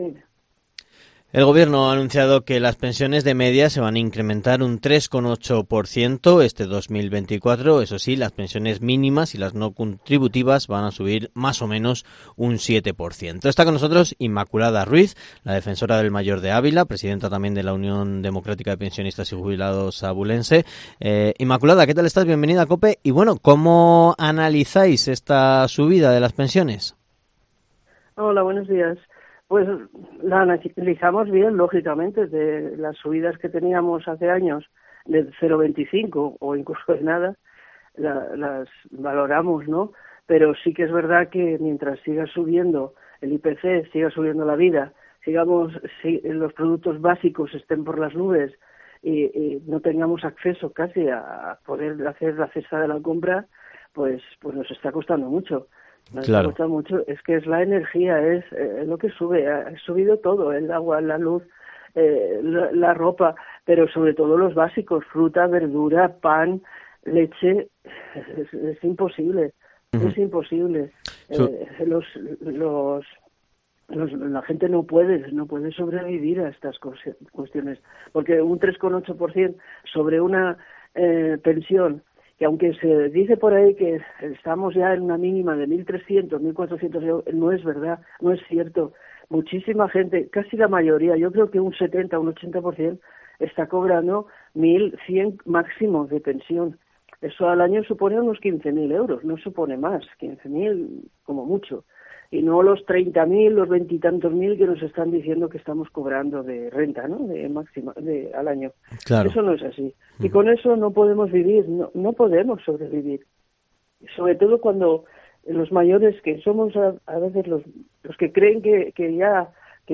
ENTREVISTA/ La defensora del mayor, Inmaculada Ruiz en COPE